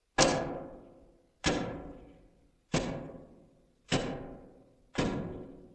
vent-walk.wav